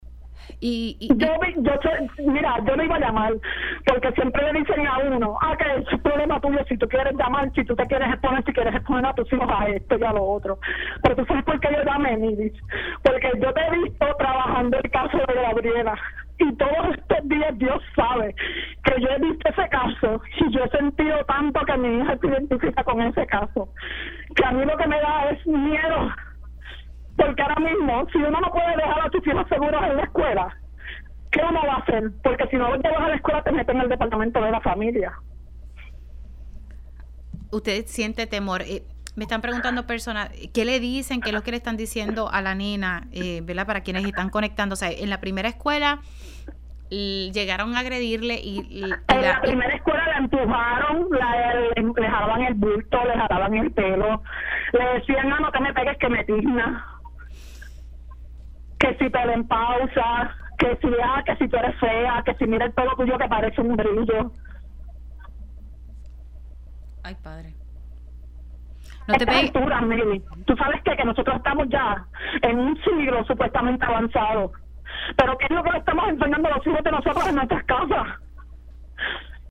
Una madre, que decidió hablar en condición de anonimato en Pega’os en la Mañana, afirmó que en ambas escuelas de nivel intermedio en las que ha estado su hija, de 12 años, ha enfrentado acoso por parte de sus compañeros y compañeras, sin que ninguna autoridad tome acción.